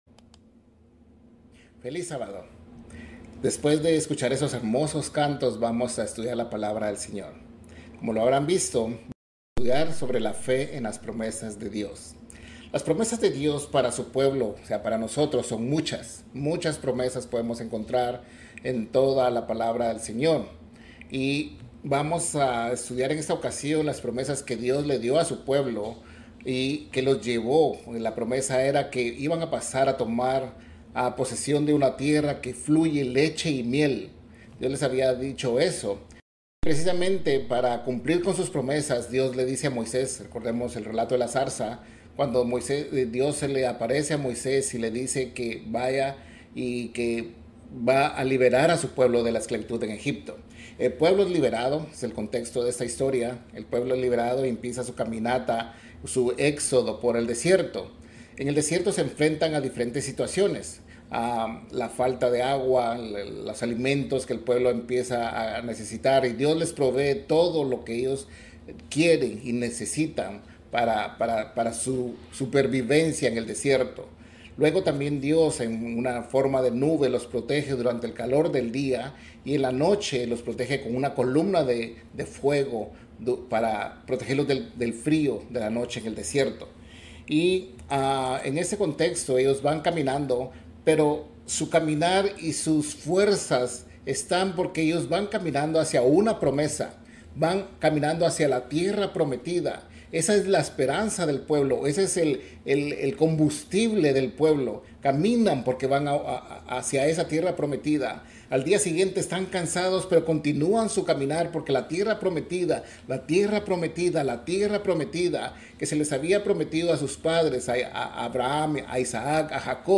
Given in Ciudad de México